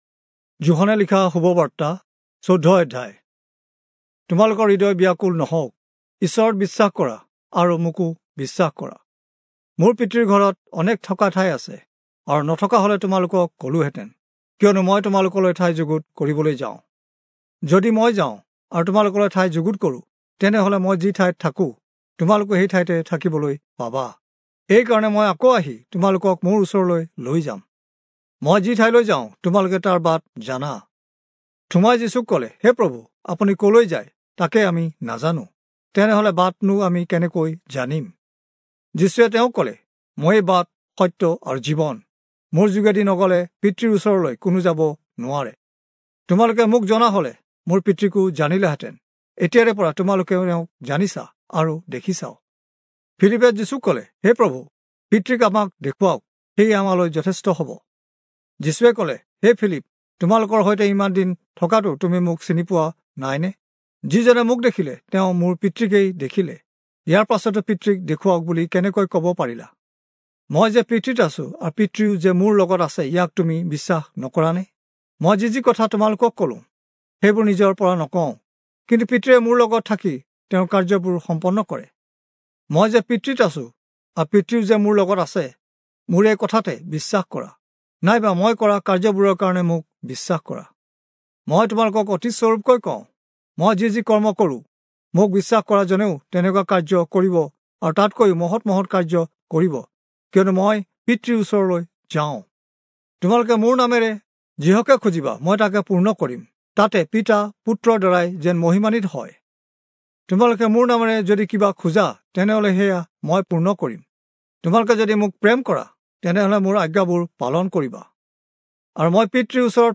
Assamese Audio Bible - John 2 in Bnv bible version